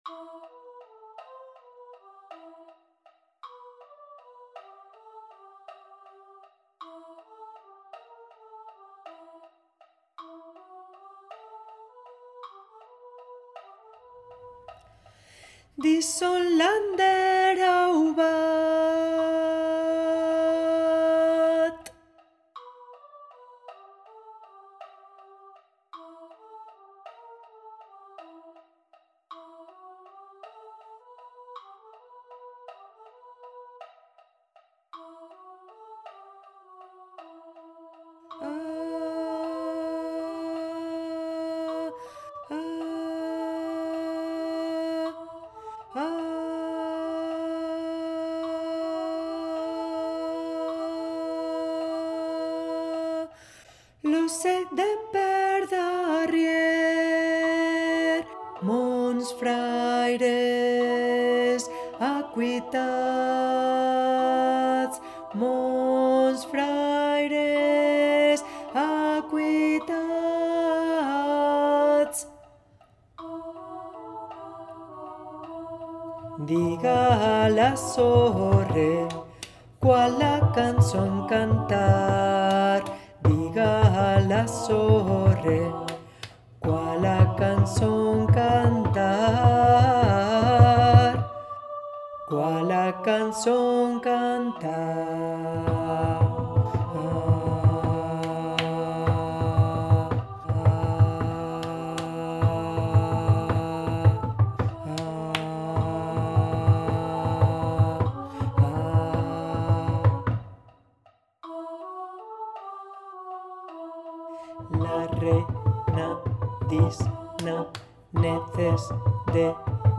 canción tradicional occitana
en arreglo para coro de voces iguales SMA.